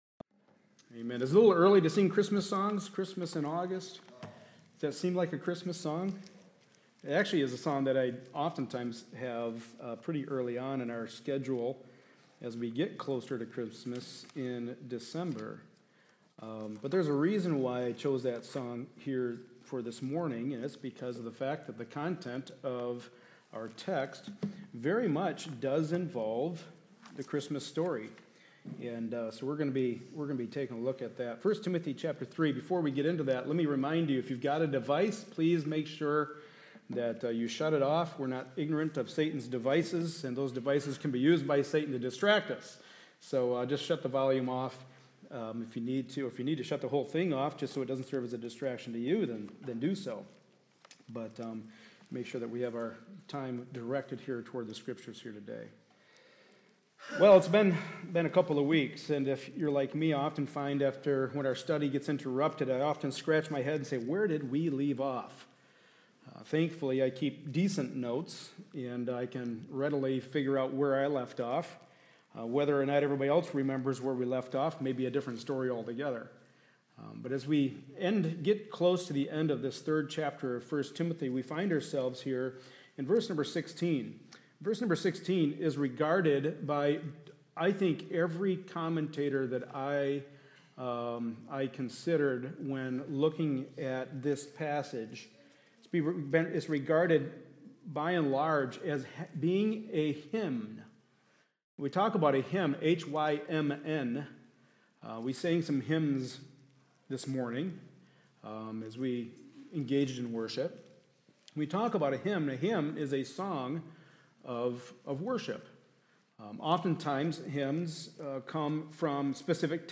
1Timothy 3:16 Service Type: Sunday Morning Service A study in the Pastoral Epistles.